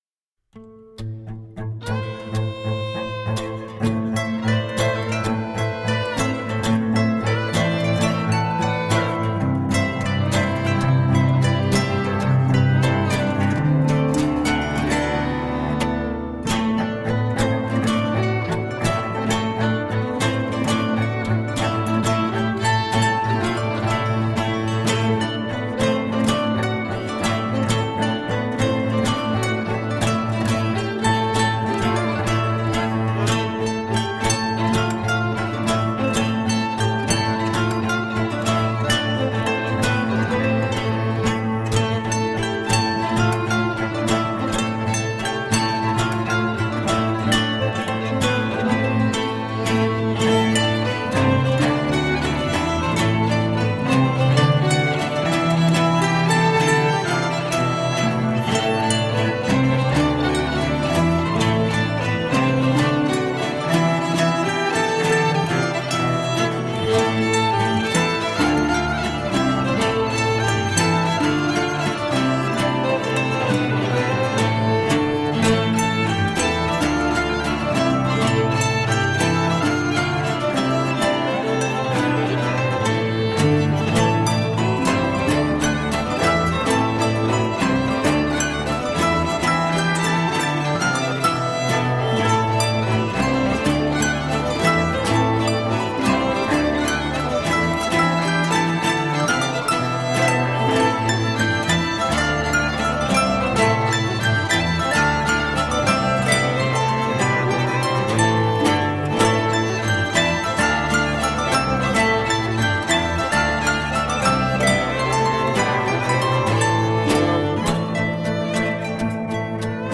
Timeless and enchanting folk music for the soul.
Tagged as: World, Classical, Folk, Celtic, Harp